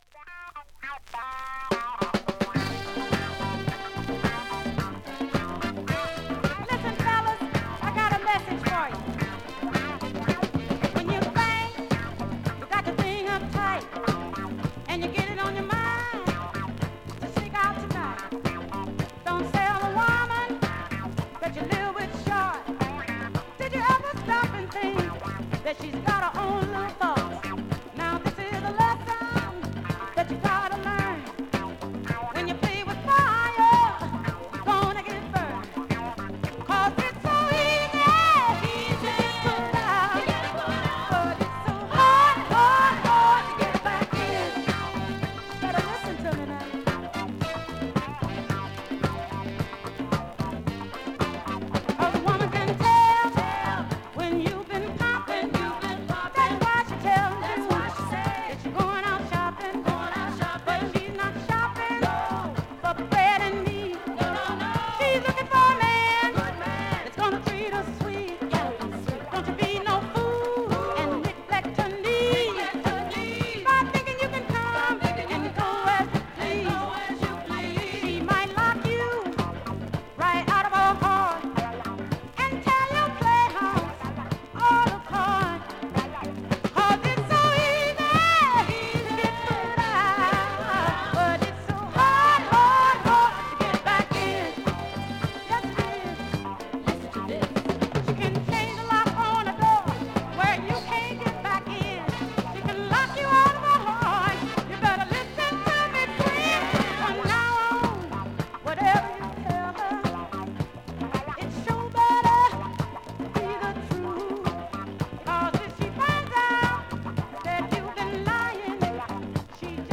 現物の試聴（両面すべて録音時間６分２０秒）できます。